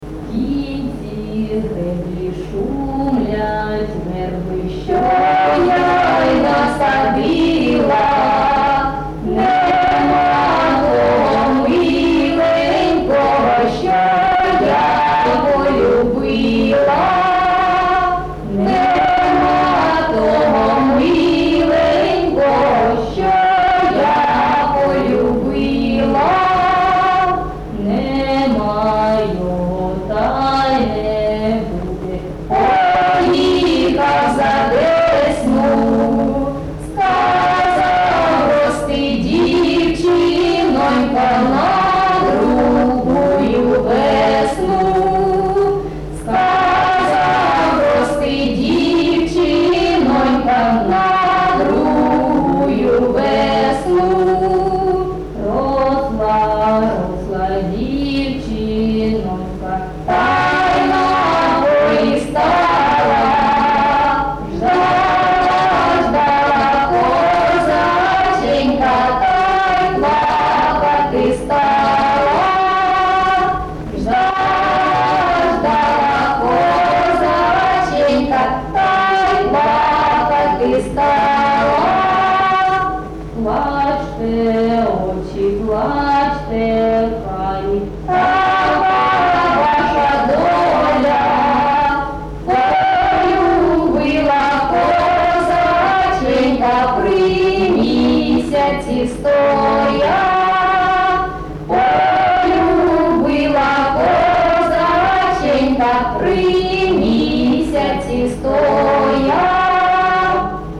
ЖанрПісні з особистого та родинного життя
Місце записус. Євсуг, Старобільський район, Луганська обл., Україна, Слобожанщина